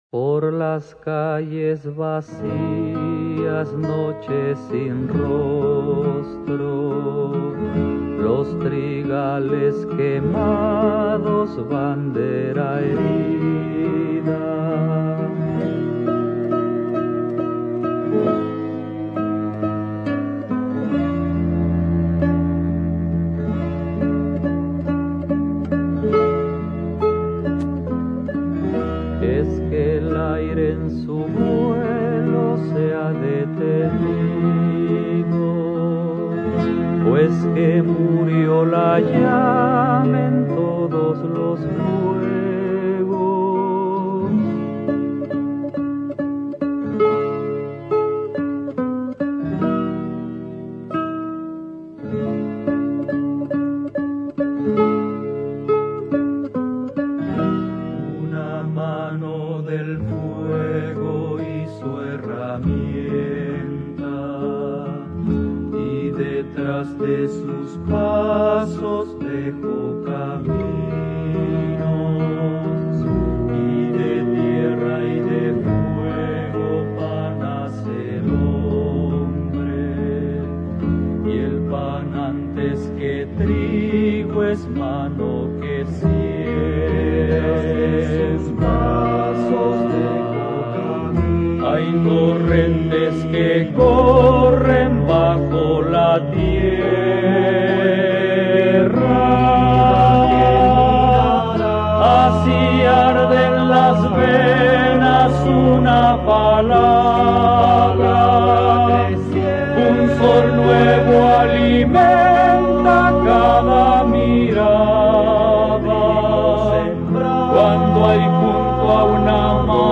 radical folk group